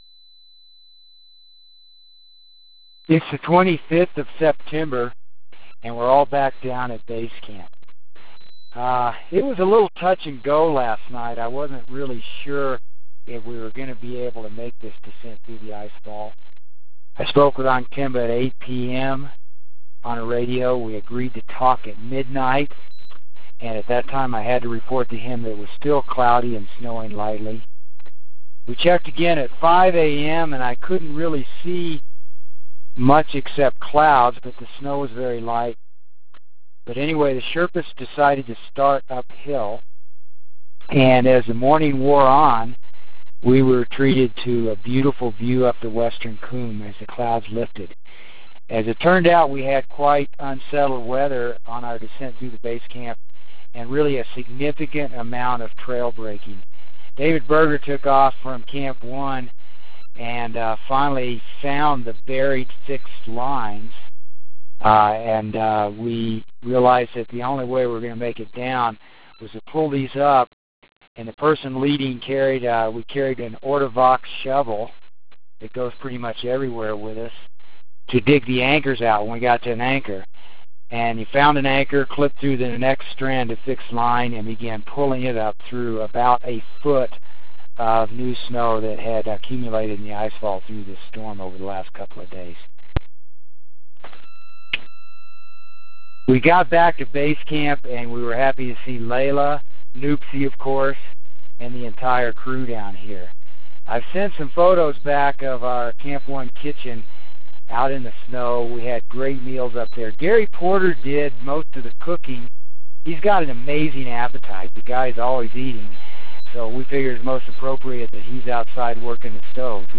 September 25 - Back in the "thick air" of Base Camp